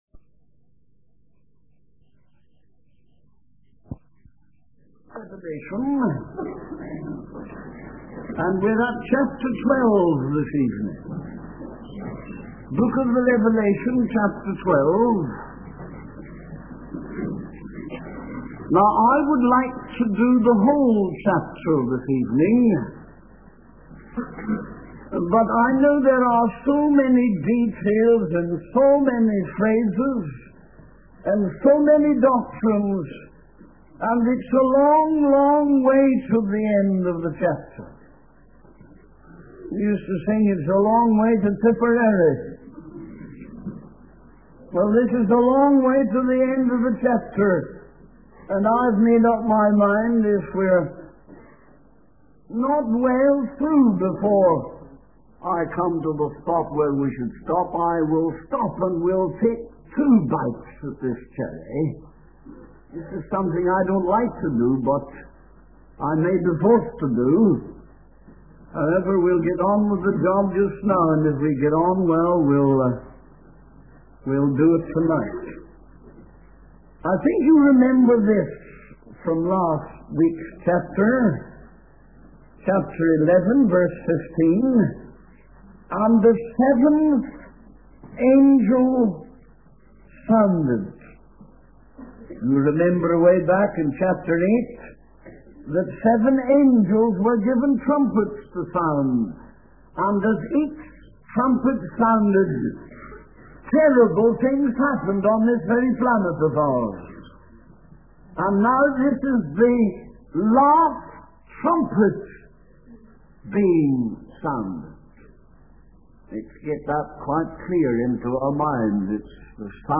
In this sermon, the preacher begins by leading the congregation in singing the hymn 'Stand Up, Stand Up for Jesus.' He then introduces the topic of the sermon, which is the woman mentioned in the book of Revelation. The preacher explains that the woman represents the church and is being persecuted by the devil.